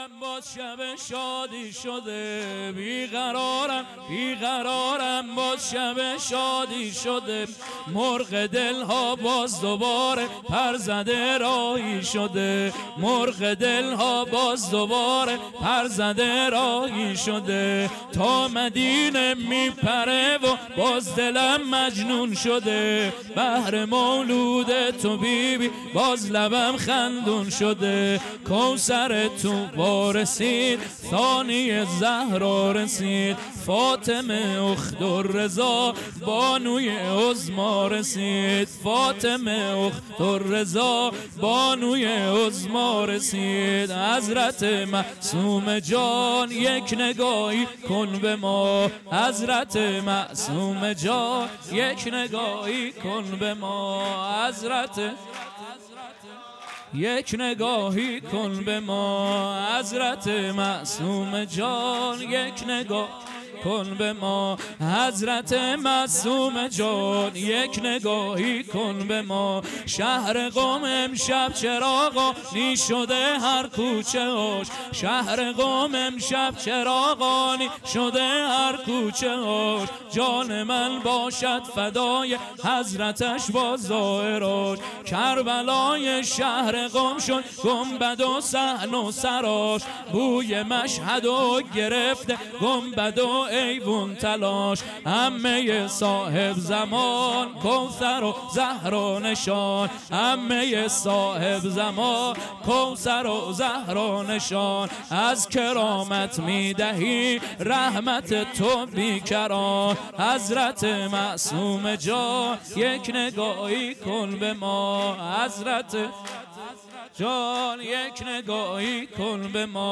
جشن دهه کرامت 1400